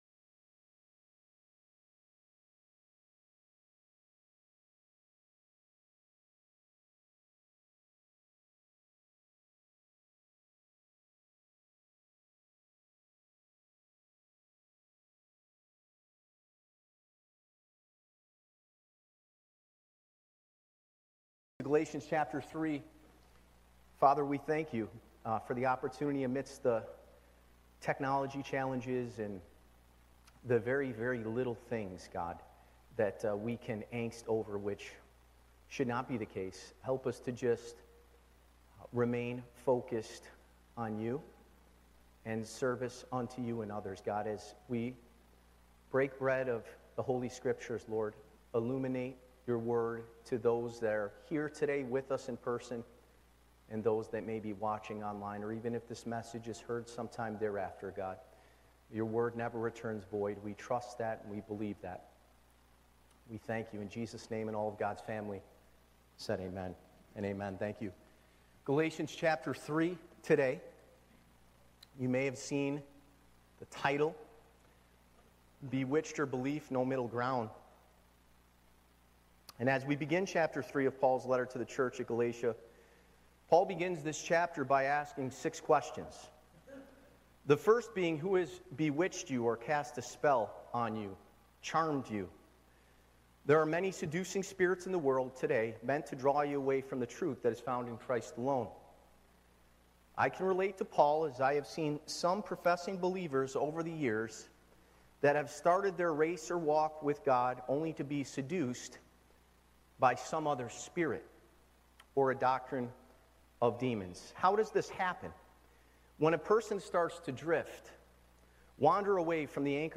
Church Location: Spencerport Bible Church
Galatians 3:1-14 Live Recording